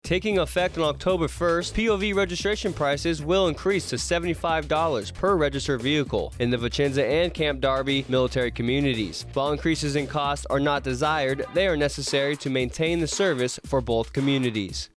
AFN Radio Spot